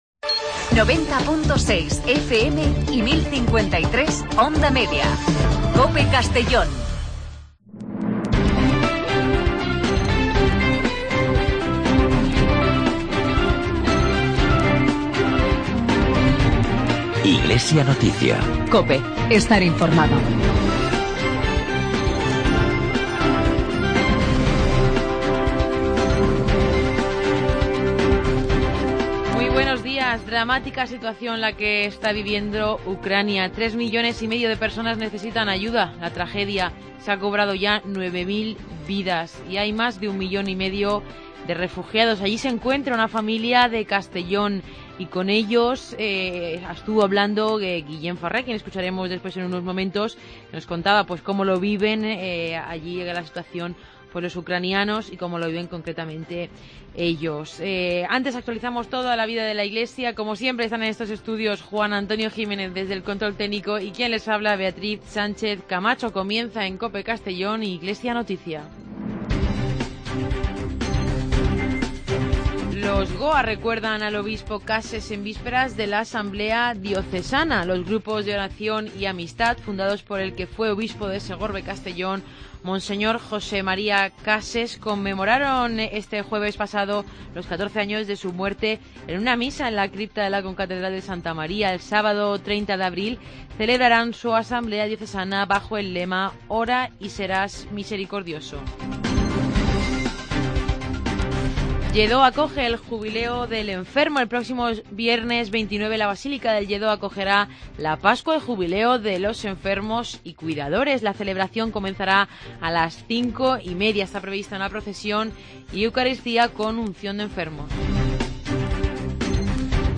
AUDIO: Espacio informativo de la actualidad diocesana de Segorbe-Castellón dirigido y presentado por